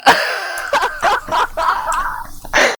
hahaha